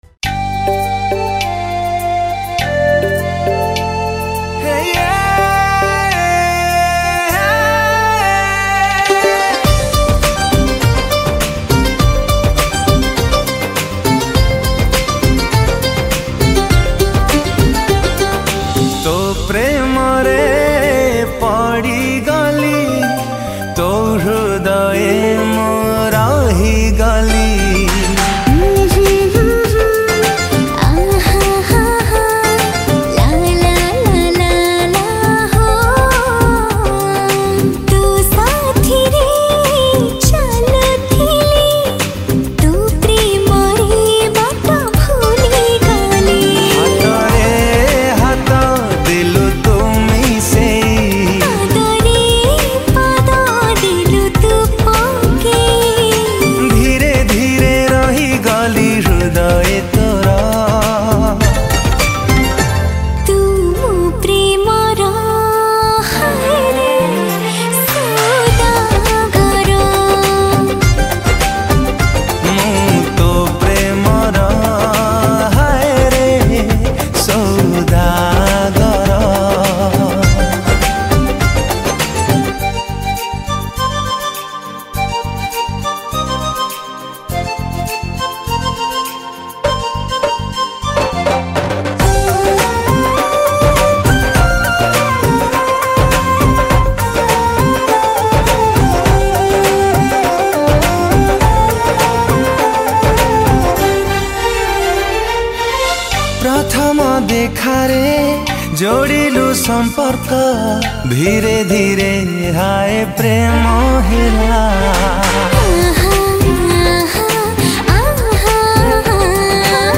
♪ Keyboard